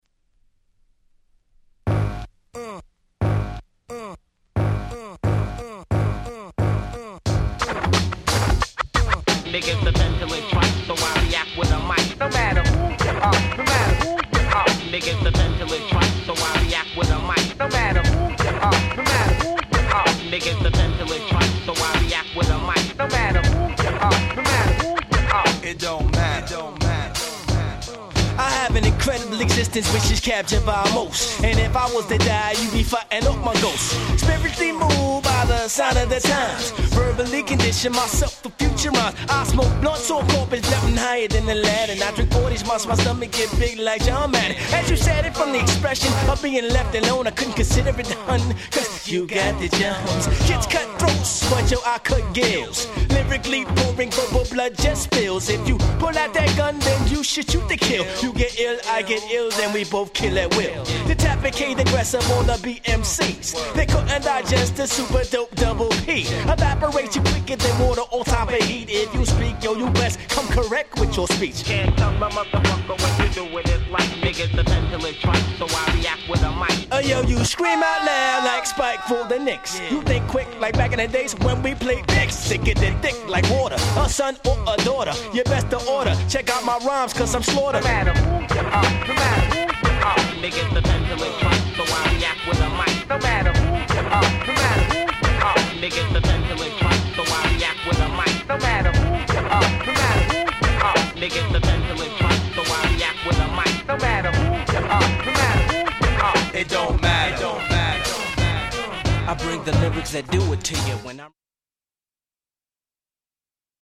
90's Underground Hip Hop Classics !!